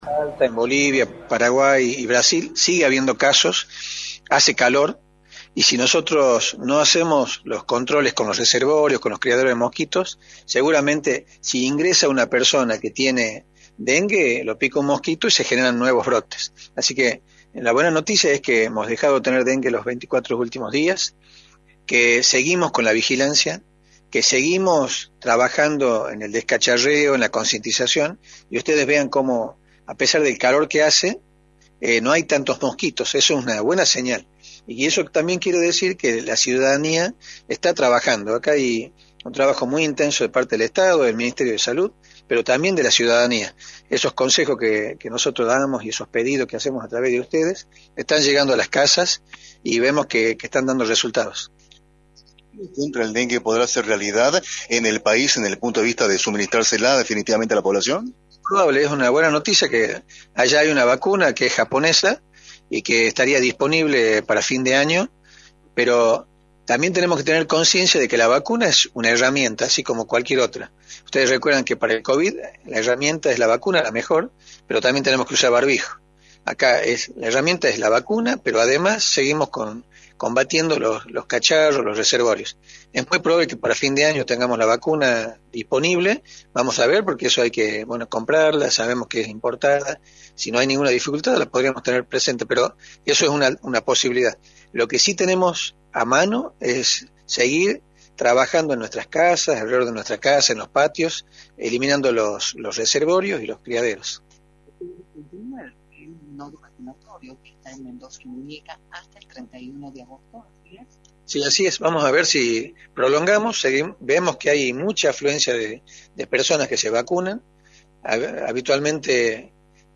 El Dr. Luís Medina Ruíz, Ministro de Salud, informó en Radio del Plata Tucumán, por la 93.9, cómo se encuentra la situación epidemiológica de la provincia, y confirmó que no fueron registrados casos de dengue en los últimos 24 días.